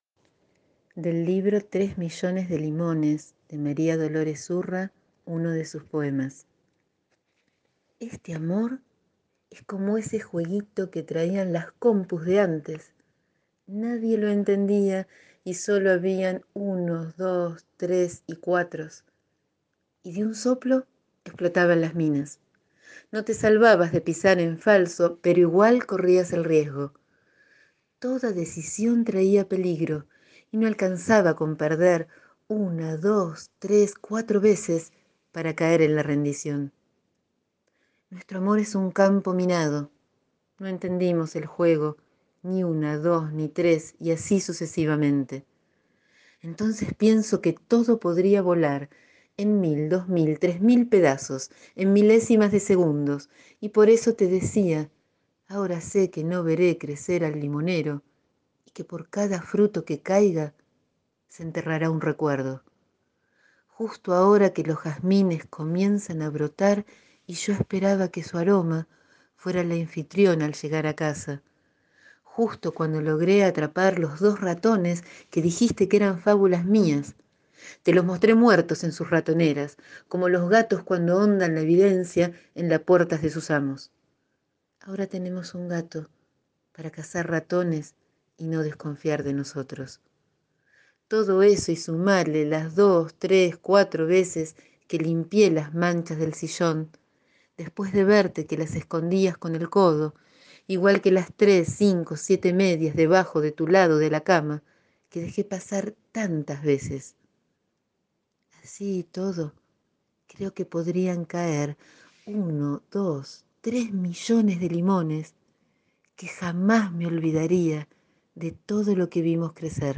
Leo hoy un poema del libro «Tres millones de limones» de Dolores Urra.